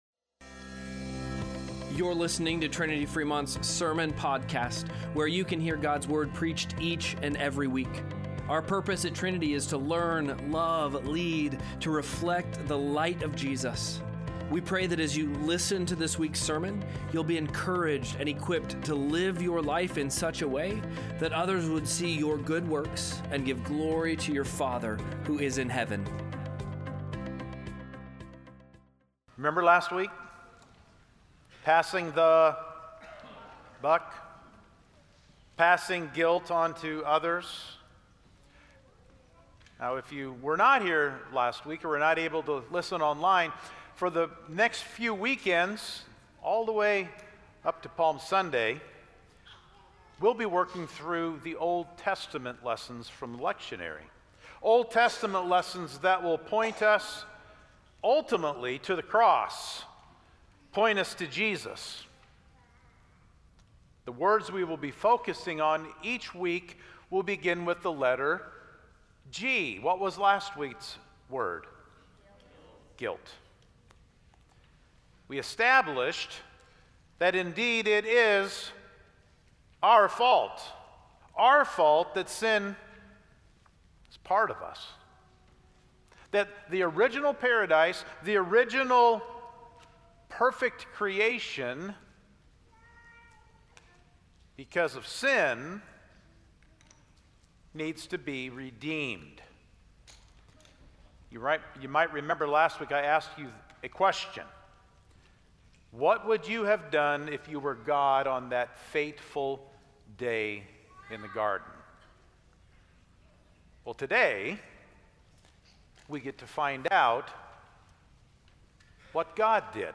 03-01-Sermon-Podcast.mp3